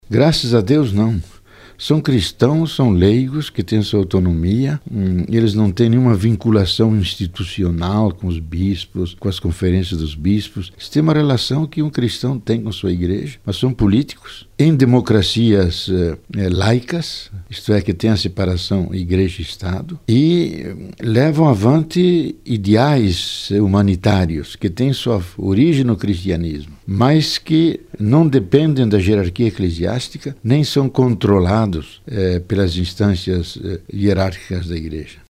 Leonardo Boff em entrevista coletiva à swissinfo, em Berna.